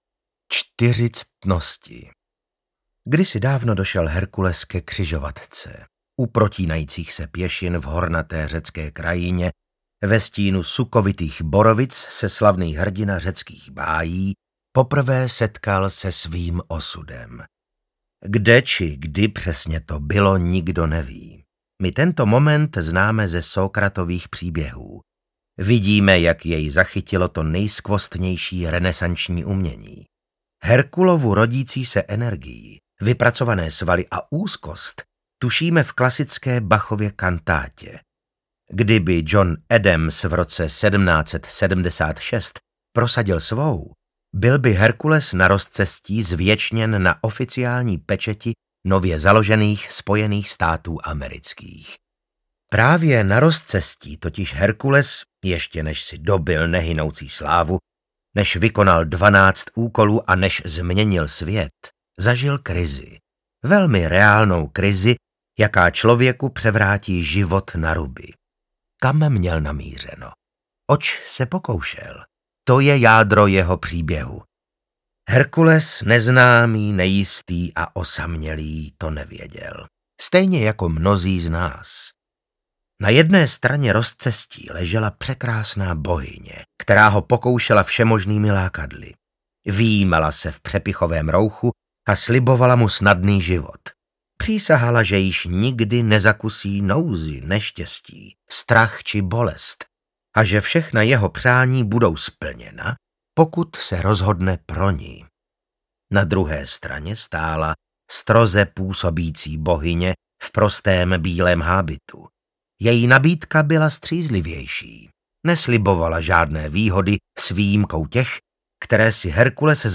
Odvaha volá audiokniha
Ukázka z knihy
• InterpretVasil Fridrich